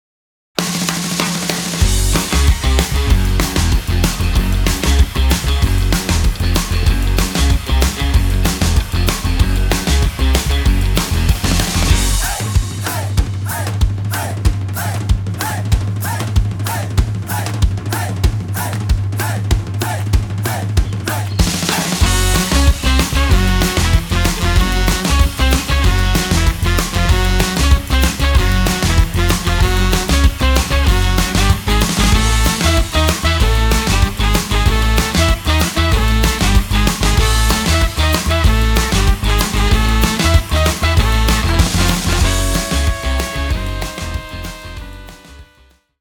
• Blues
• Jazz
• Rock and roll
internationalt swing- og jive-orkester med kant og klasse
Lead Vocal / Guitar
Saxophone / Vocal
Trumpet / Vocal
Double Bass
Drums